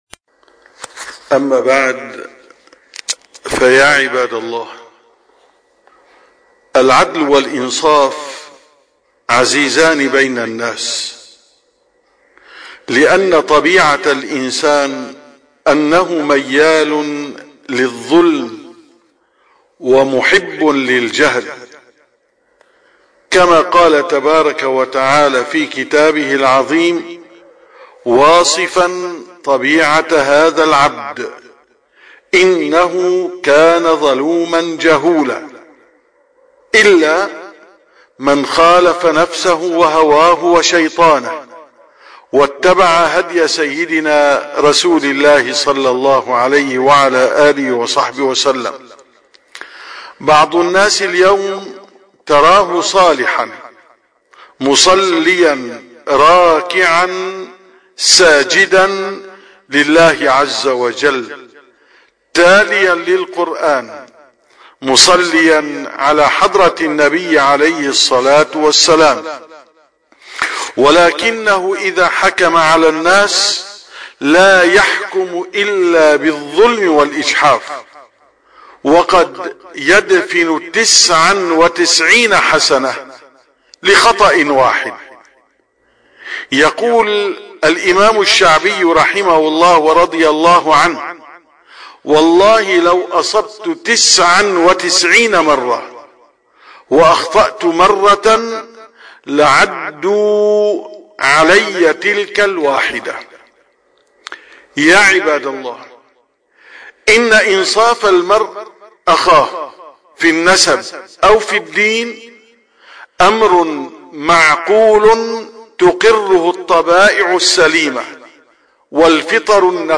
951ـ خطبة الجمعة: العدل من صفات أهل الكمال